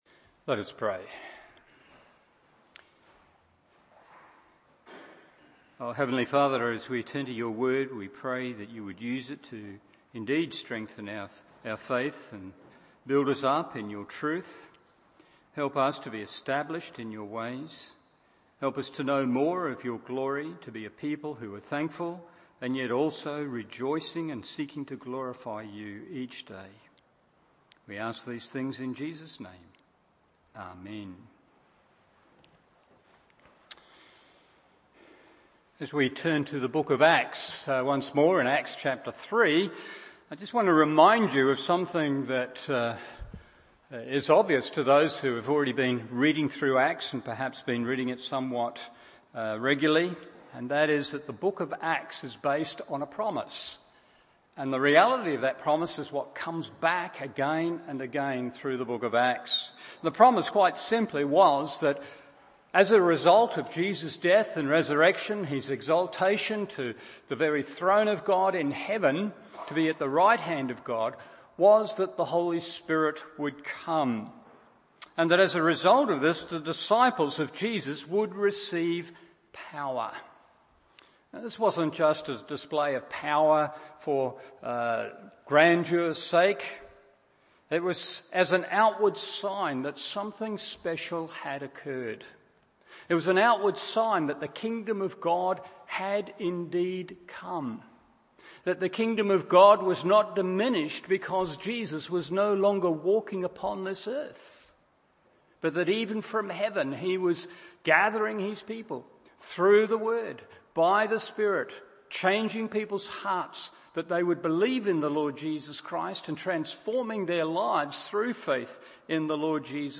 Evening Service Acts 3:11-26 1.